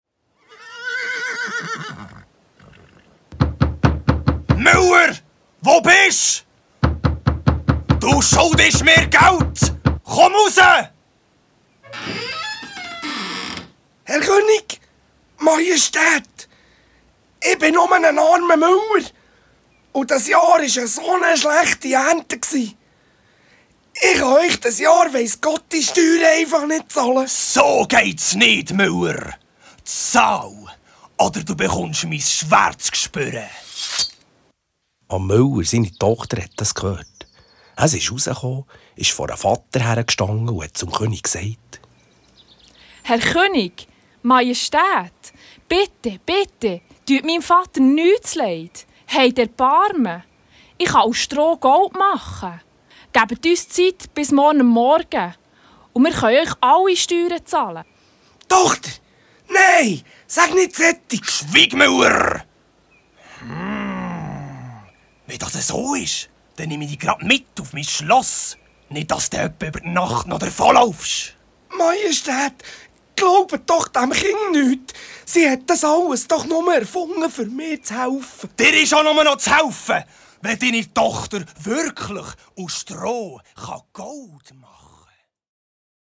Hörbuch mit drei Abenteuergeschichten von Papagallo & Gollo, drei traditionellen Märchen (Rumpustiuzli, Haas u dr Igu, Rotchäppli) und zwei Liedern in breitem Berndeutsch.